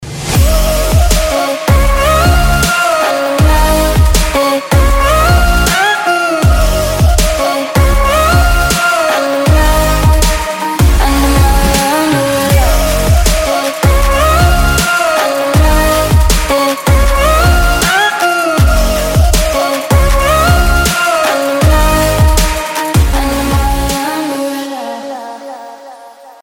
• Качество: 320, Stereo
dance
Electronic
Красивый ремикс на кавер